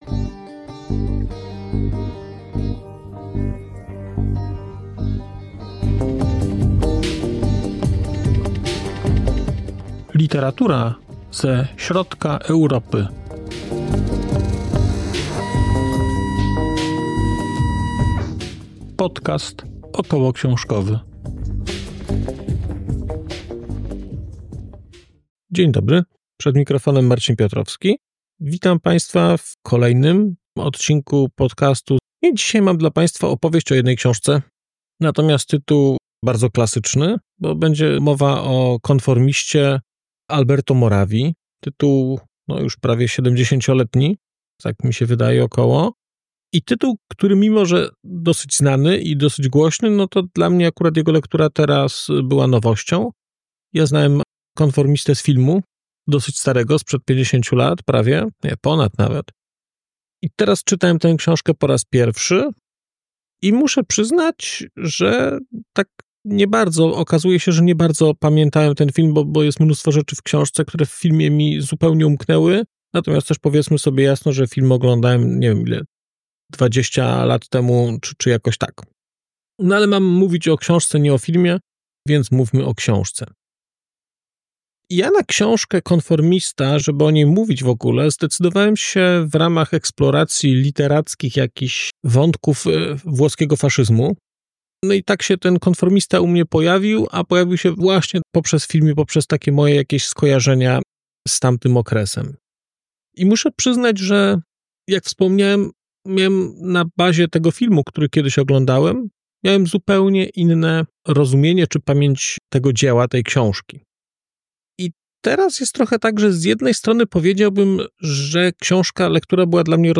🔧 odcinek zremasterowany: 6.04.2025